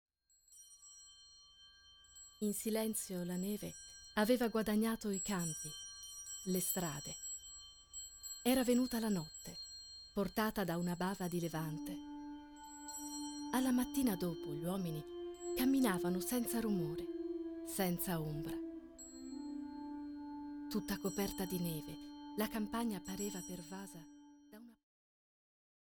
Suoni e parole di natale: I brani più famosi della tradizione natalizia arrangiati per ensemble di flauti di bambù, alternati a letture di estratti da racconti di vari autori e generi sul Natale.